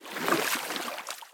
alien-biomes / sound / walking / water-10.ogg
water-10.ogg